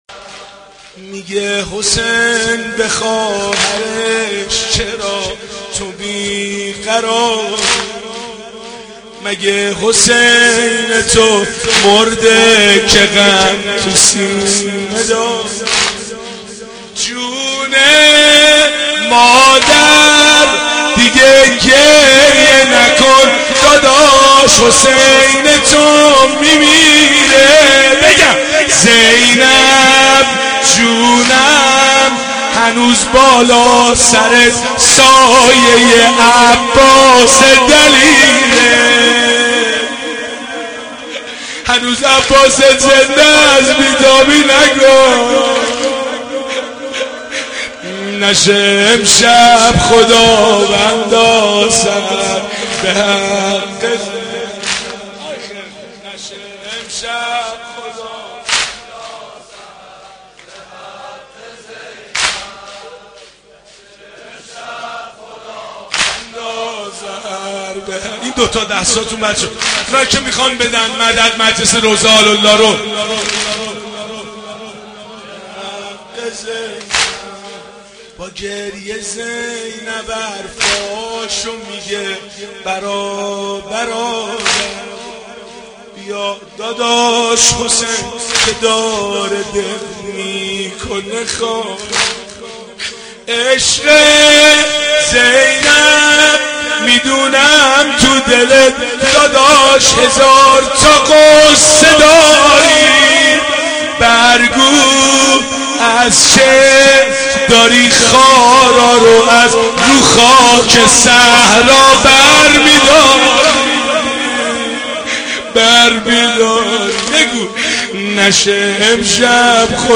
محرم 88 - سینه زنی 1
محرم-88---سینه-زنی-1